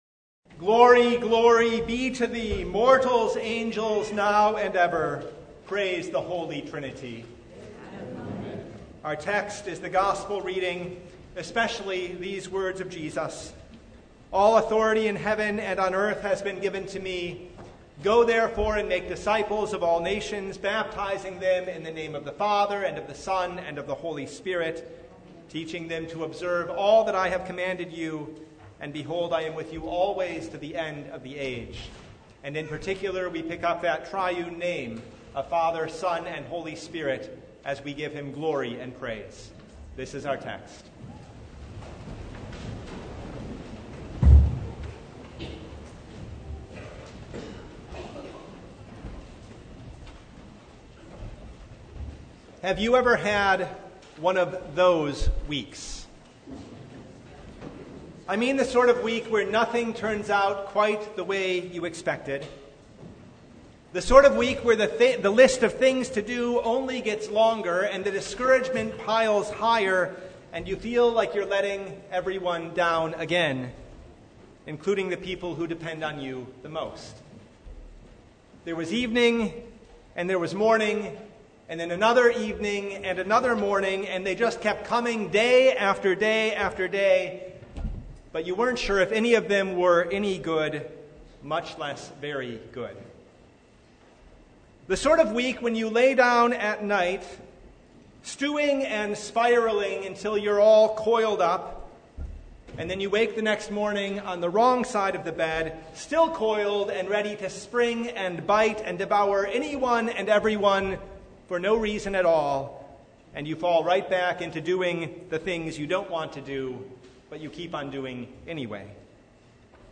Sermon from The Feast of the Holy Trinity (2023)